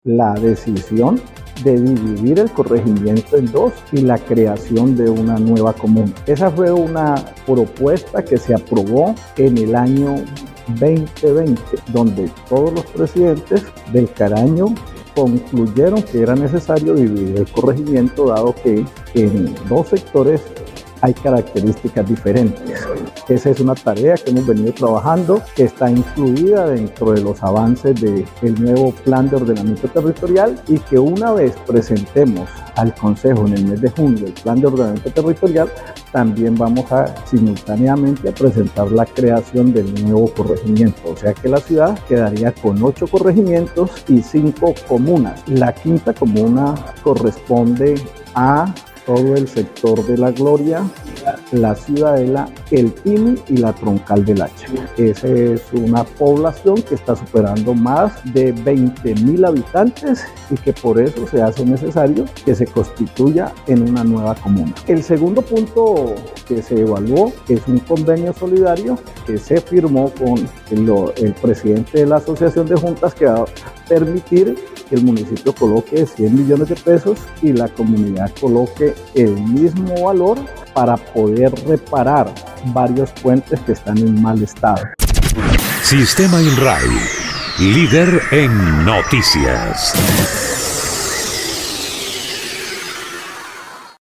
Luis Antonio Ruiz Cicery, alcalde del municipio de Florencia, explicó que la quinta comuna estará conformada por barrios y sectores como La Gloria, La Ciudadela Habitacional Siglo 21, El Timy, junto a la Troncal del Hacha, con una población superior a los 20 mil habitantes.
El anuncio fue hecho por el mandatario local en medio de una reunión con presidentes de juntas de acción comunal del corregimiento El Caraño, donde, además, anuncio una millonaria inversión en materia de infraestructura vial donde se recuperarán varios puentes que se encuentran en mal estado.
02_ALCALDE_LUIS_ANTONIO_RUIZ_CICERI_CORREGIMIENTO.mp3